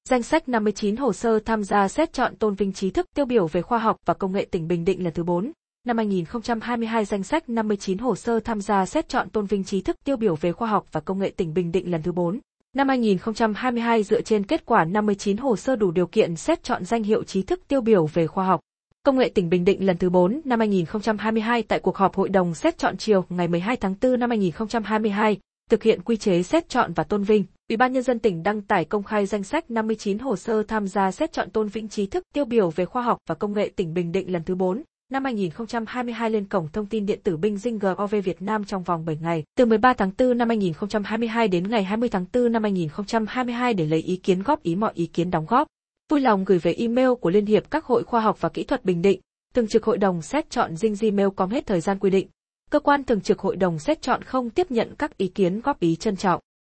Giọng nữ miền Bắc